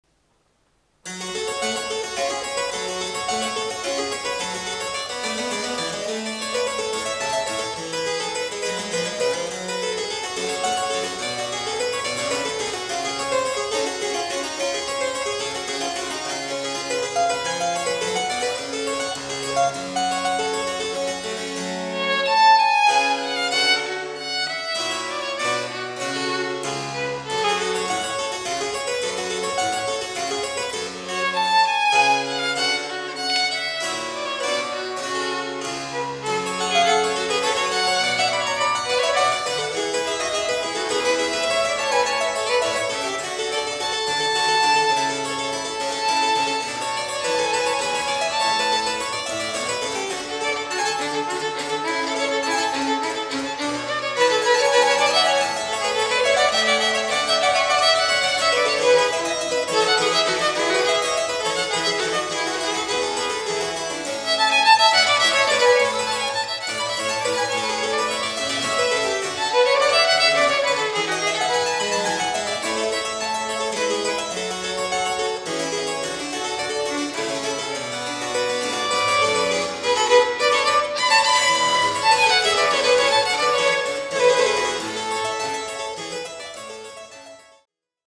This year, I also participated in the Bachfest as performer in one of the free concerts in the Sommersaal on 6 May at 3 pm, playing
Baroque Violin